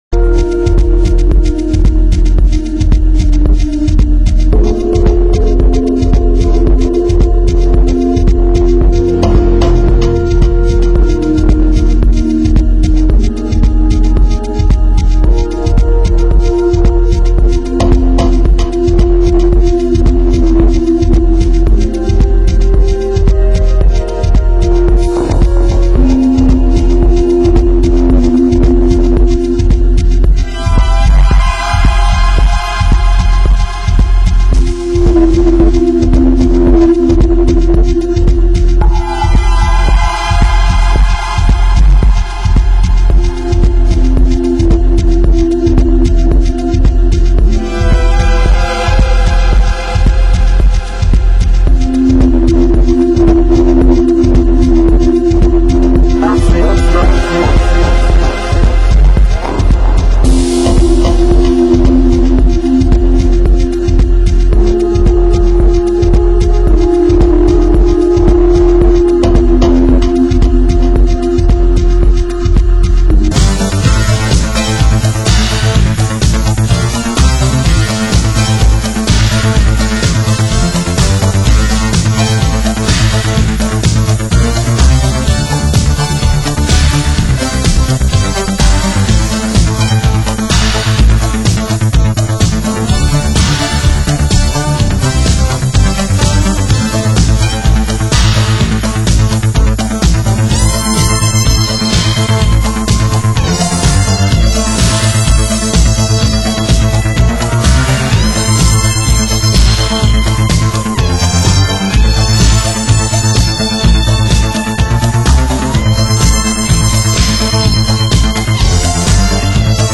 Format: Vinyl 12 Inch
Genre: Disco